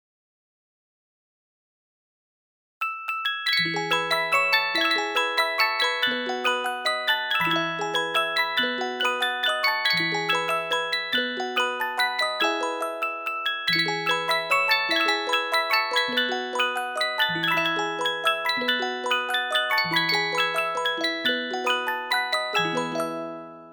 Old Music Box EDELWEISS Records
Needless to say that the quality of these recordings is poor, if measured with nowadays (CD) standards.